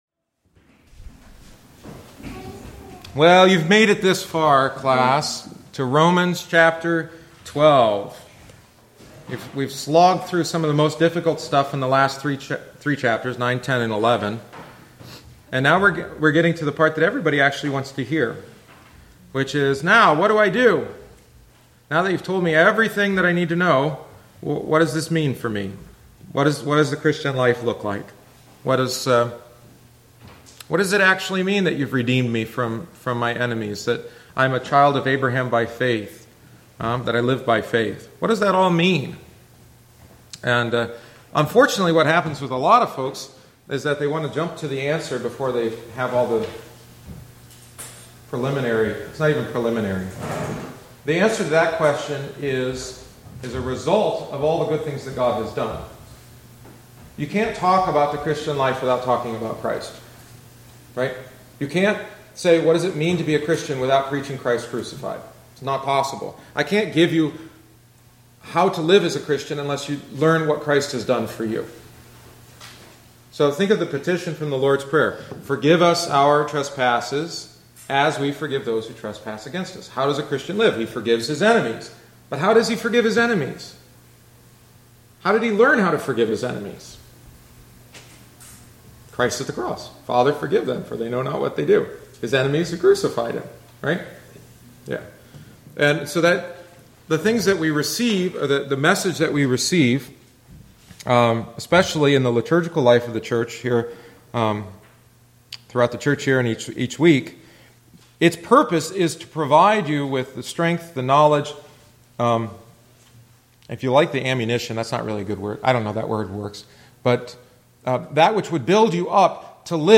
The following is the twenty-seventh week’s lesson.